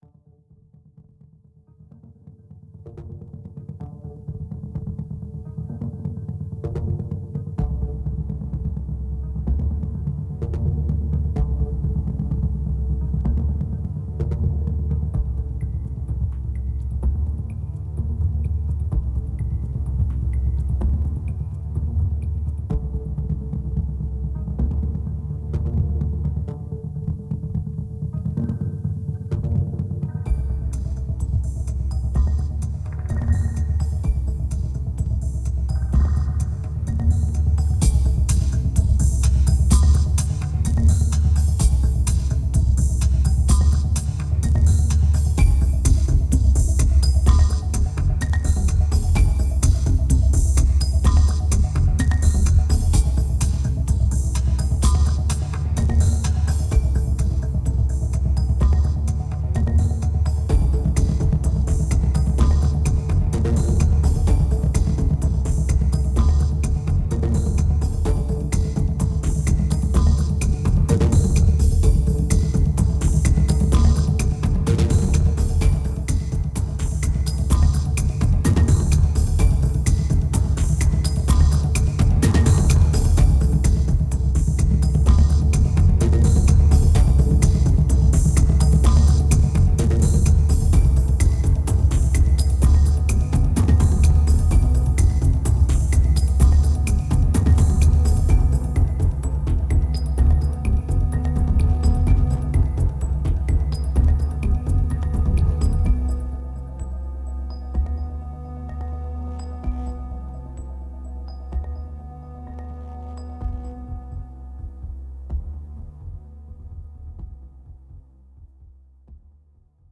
2372📈 - 31%🤔 - 127BPM🔊 - 2010-03-08📅 - -41🌟